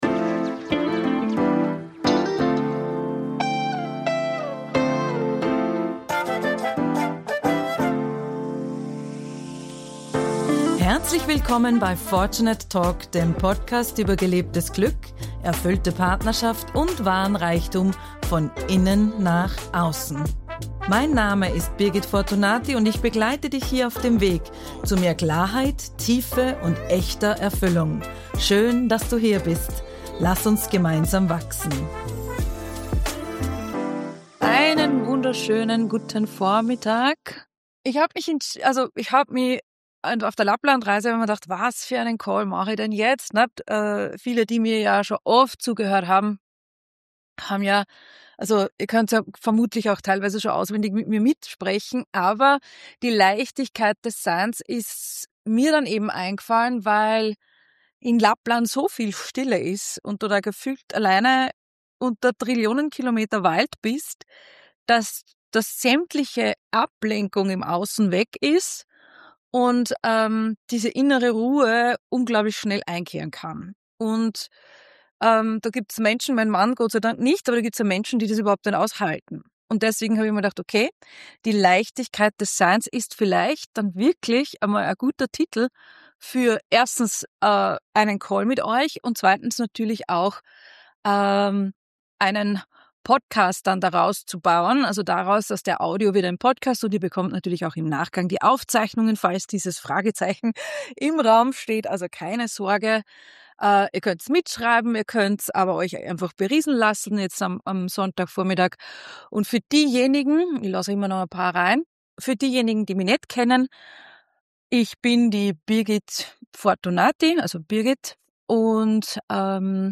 #008 Die Leichtigkeit des Seins - Live-Call Ausschnitt